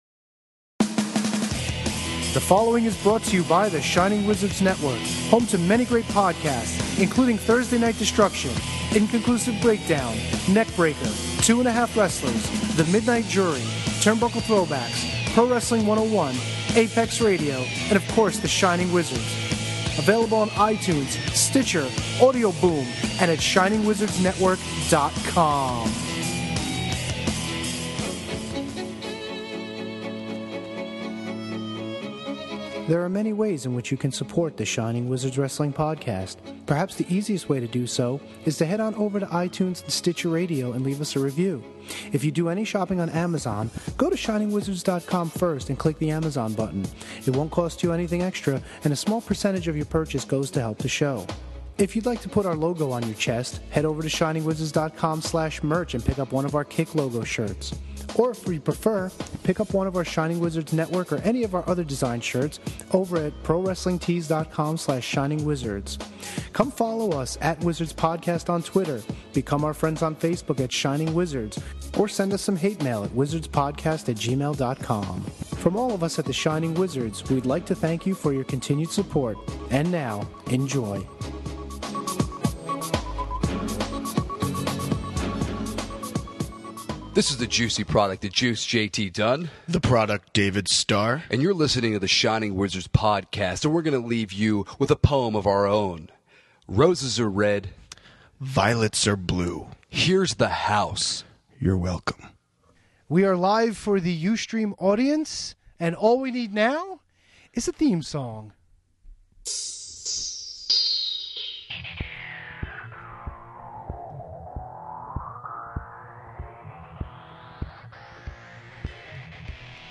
The boys spent this live episode dissecting the Art of Wrestling podcast episode featuring CM Punk, going in-depth about what was said, what was assumed that turned out to be true about the WWE, and what this has meant and will mean to the business.